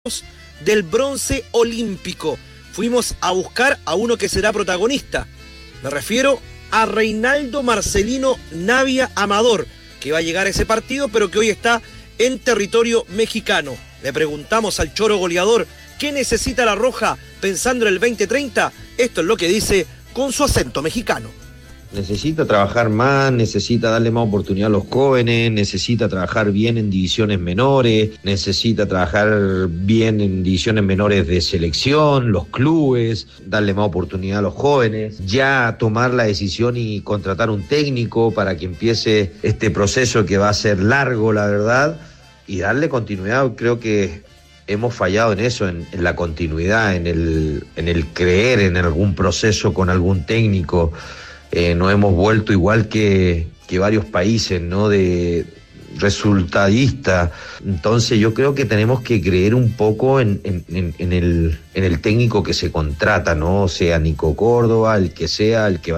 En conversación con ADN Deportes, Reinaldo el “Choro” Navia entregó sus claves para volver a tener una selección chilena competitiva.